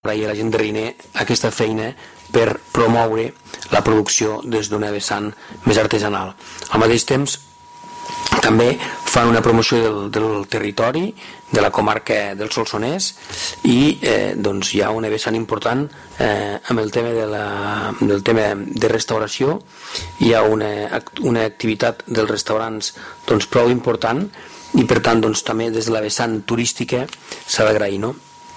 Presentació de la Fira de la Mel de Riner
El vicepresident quart, Jordi Latorre, agraeix als veïns i productors de Riner la feina de promoure l’elaboració artesanal de la mel i promocionar el territori aportant dinamisme econòmic i gastronòmic